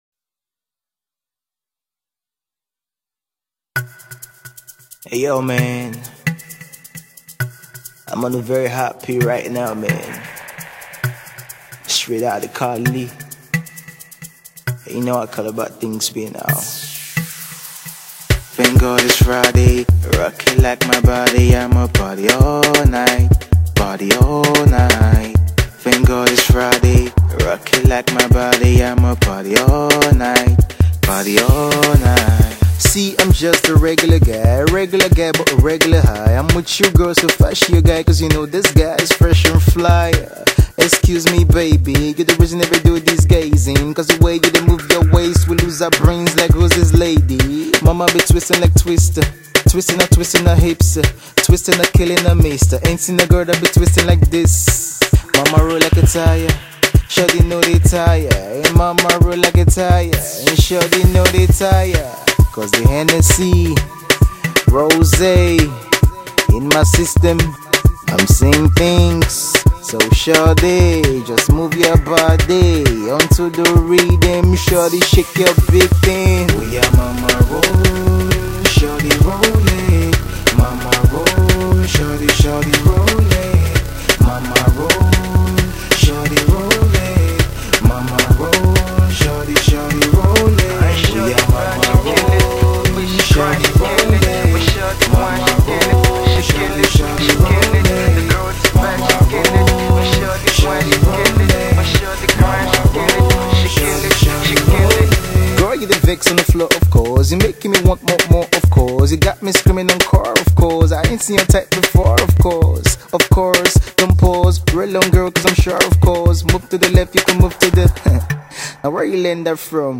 is an even bigger smash for the clubs.